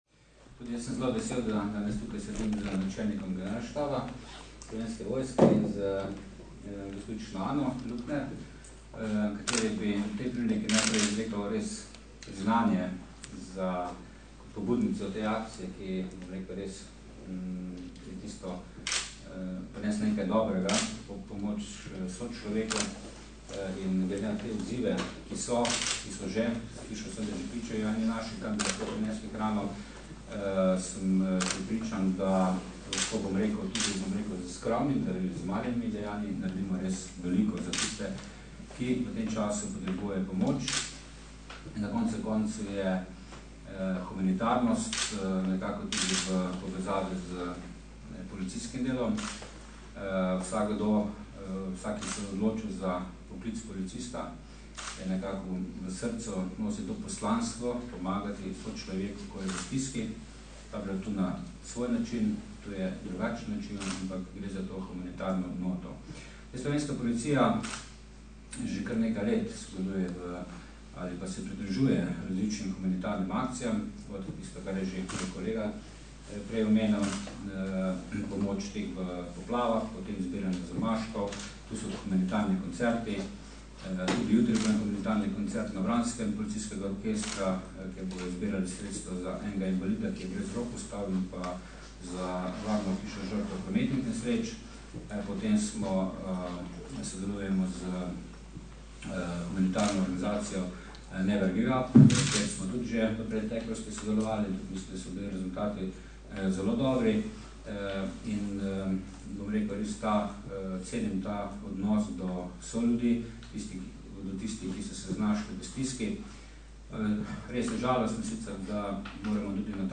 Sodelovanje smo predstavili na današnji novinarski konferenci.
Zvočni posnetek izjave Stanislava Venigerja (mp3)